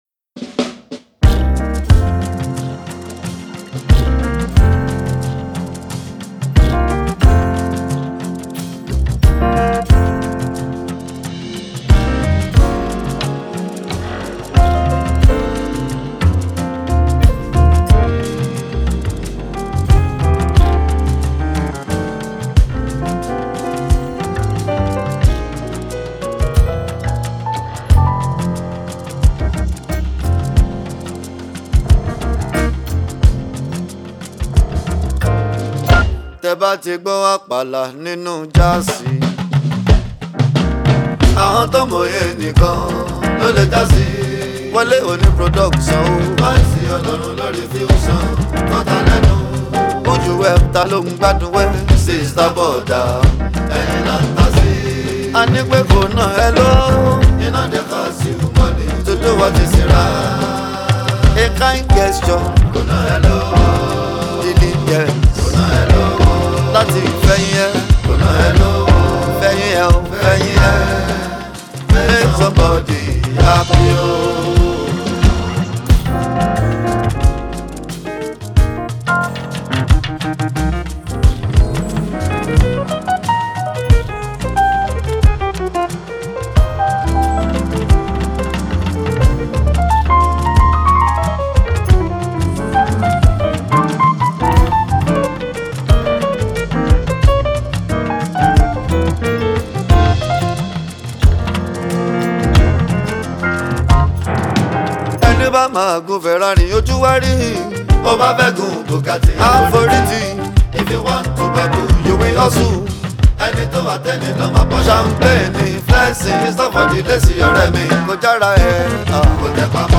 Blended Wine Of Apala with Jazz Music
popular Apala gospel music group